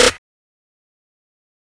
Waka Snare - 3 (2).wav